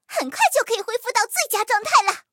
M4谢尔曼小破修理语音.OGG